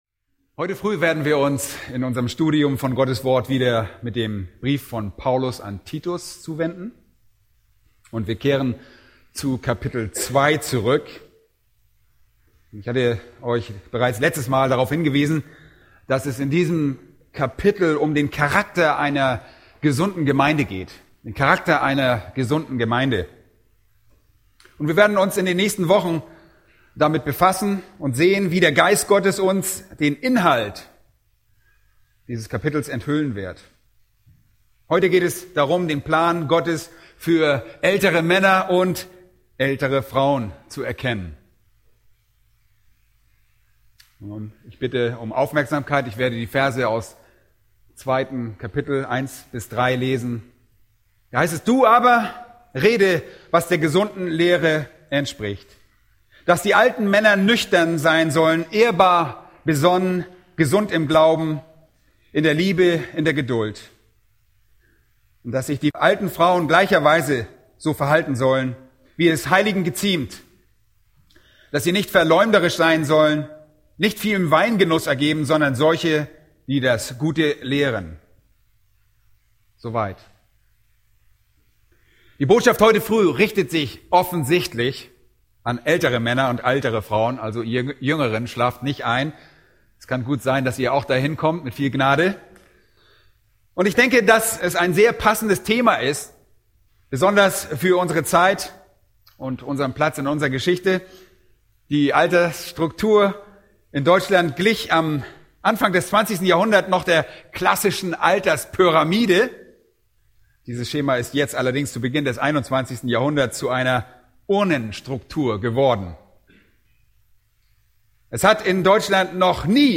Ohne einen gottesfürchtigen Lebensstil ist nicht nur jede Evangelisation unglaubwürdig, der Herr der Gemeinde selbst wird dadurch verlästert. Damit genau das Gegenteil geschieht, unterweist Paulus im Titusbrief einen Gemeindeleiter, wie die Gemeindeglieder ihre biblische Erkenntnis ganz praktisch ausleben: Ob jung, ob alt, ob Mann oder Frau – jeder wird hier herausgefordert und zu einem Leben als Licht in dunkler Umgebung motiviert. ___ * Diese adaptierte Predigtserie von John MacArthur ist genehmigt durch "Grace to You".